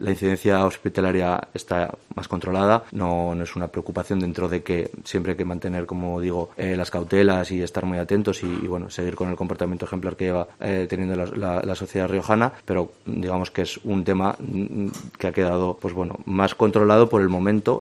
Álex Dorado, portavoz del Gobierno de La Rioja